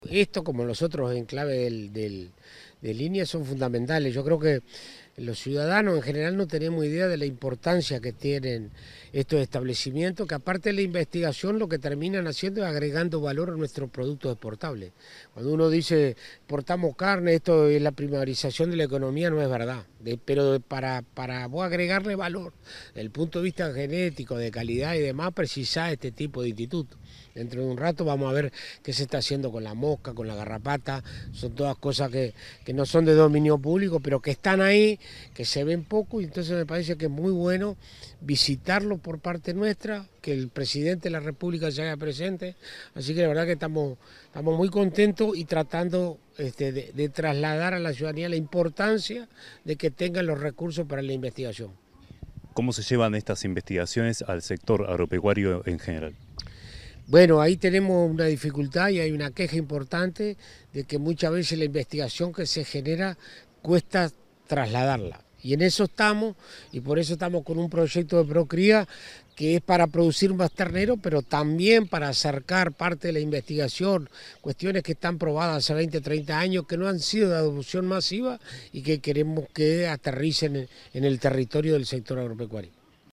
Declaraciones del ministro de Ganadería, Alfredo Fratti
Tras la visita del presidente de la República, Yamandú Orsi, a la estación experimental La Estanzuela, en Colonia, el ministro de Ganadería,
Declaraciones del ministro de Ganadería, Alfredo Fratti 31/07/2025 Compartir Facebook X Copiar enlace WhatsApp LinkedIn Tras la visita del presidente de la República, Yamandú Orsi, a la estación experimental La Estanzuela, en Colonia, el ministro de Ganadería, Agricultura y Pesca, Alfredo Fratti, efectuó declaraciones a la prensa acerca de la importancia de la investigación en el sector agropecuario.